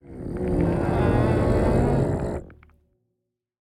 Minecraft Version Minecraft Version latest Latest Release | Latest Snapshot latest / assets / minecraft / sounds / mob / warden / agitated_3.ogg Compare With Compare With Latest Release | Latest Snapshot
agitated_3.ogg